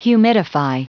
Prononciation du mot humidify en anglais (fichier audio)
Prononciation du mot : humidify